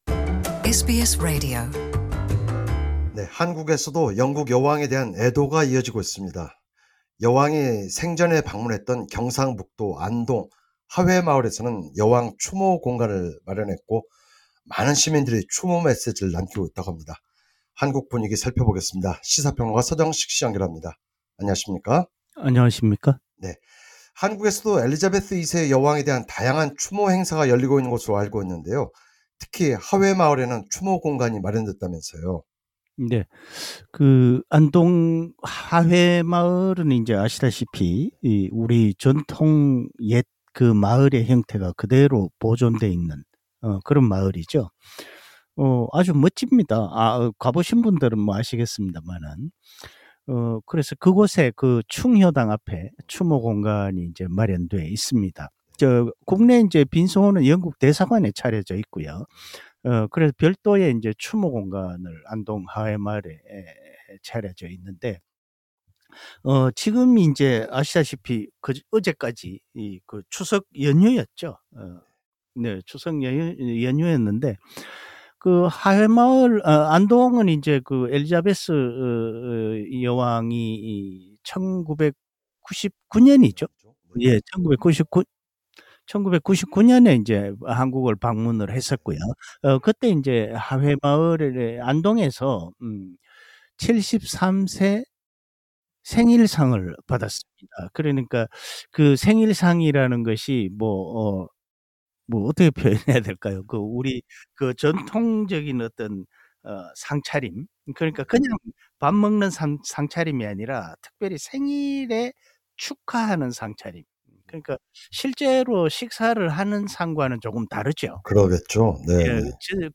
해설: 시사 평론가